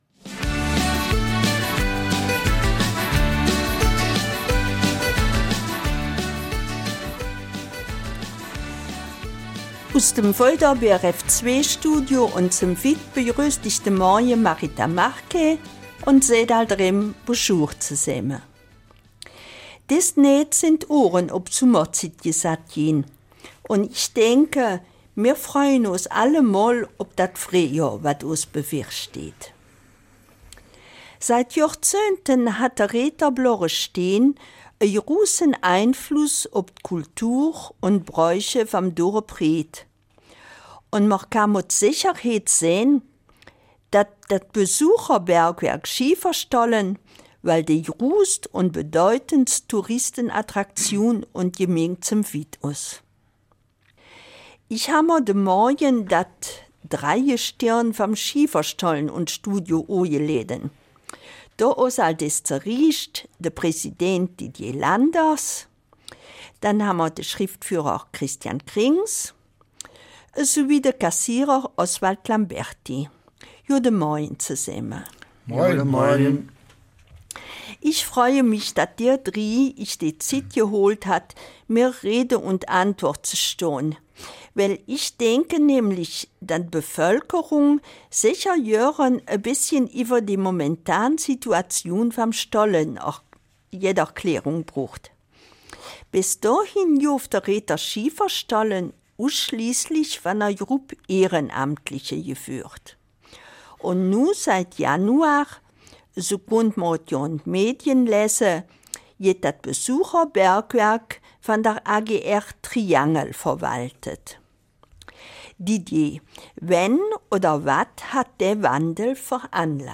Eifeler Mundart: VoG Schieferstollen Recht und AGR Triangel - Zukunftsorientierte Zusammenarbeit.